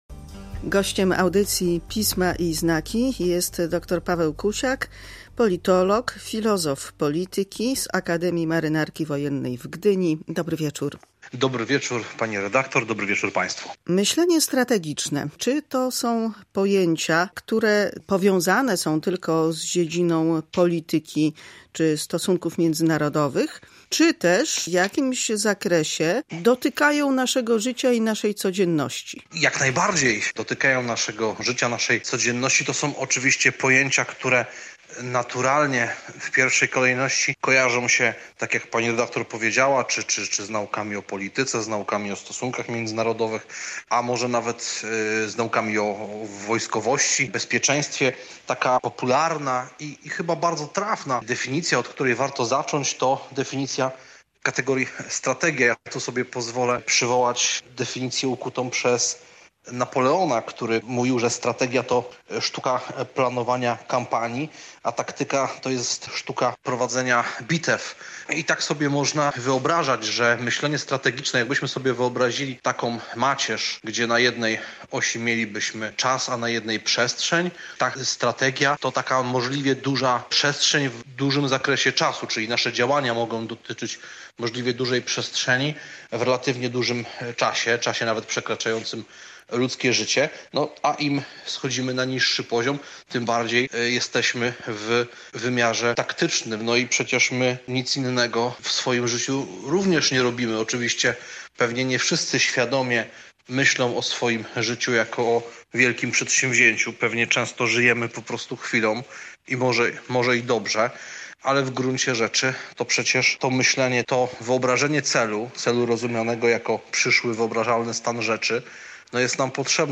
W audycji Pisma i znaki rozmowa o myśleniu strategicznym, które przydaje się w życiu prywatnym, a nie wyłącznie w polityce międzynarodowej.